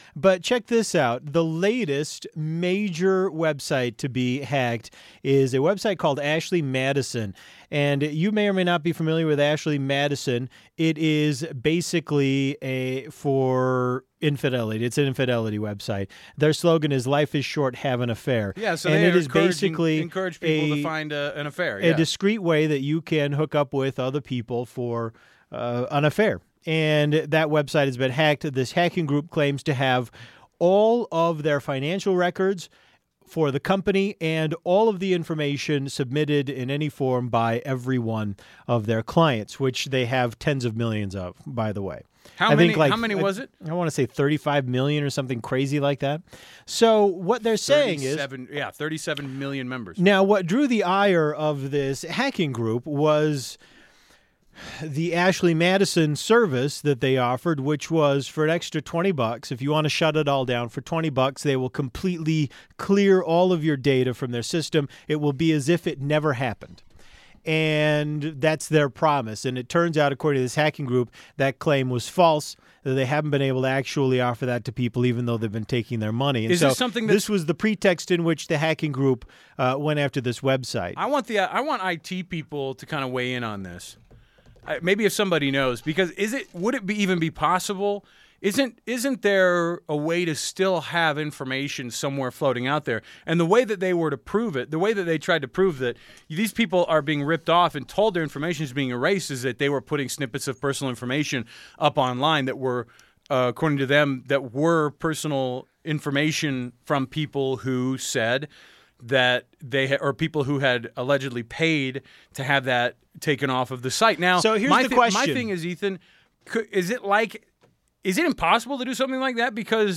The guys debate whether or not the site's patrons deserve to have the personal info exposed.